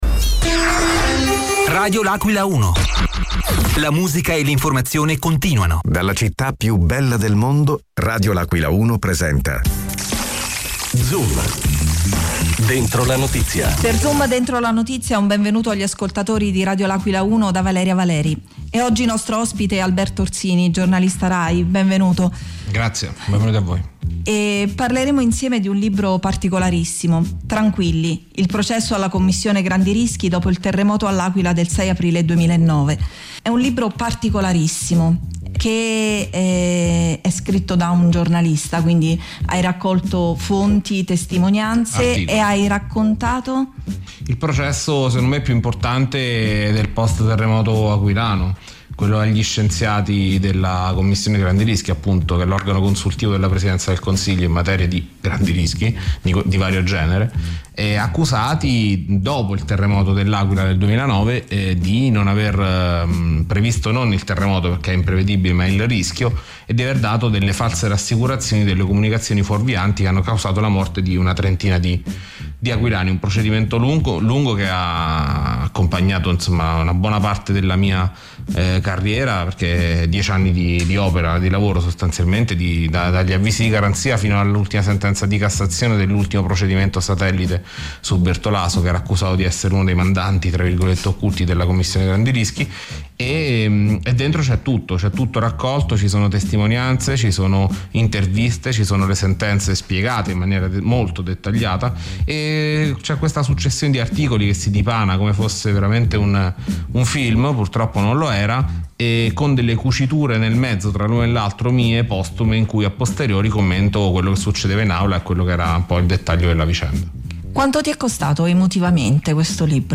Negli studi di Radio L’Aquila 1